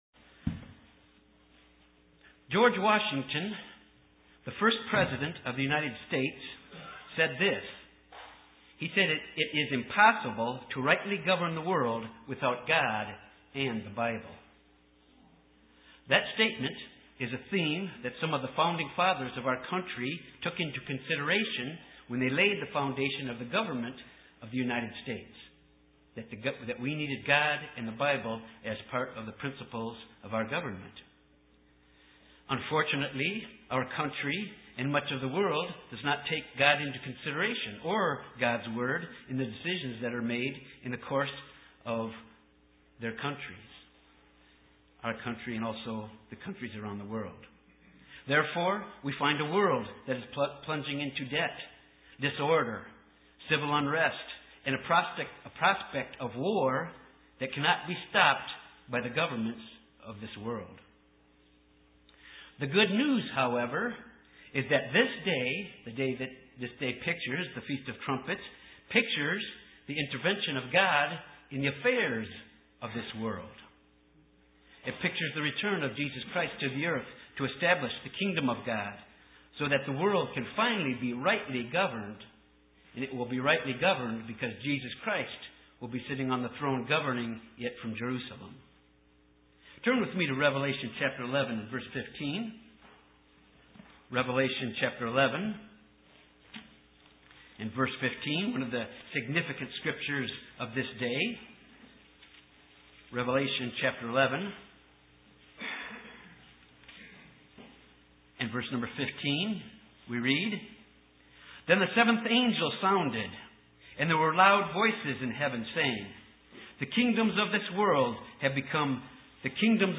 This sermon examines three facets of how the trumpets are used as a warning device to God’s people and the world.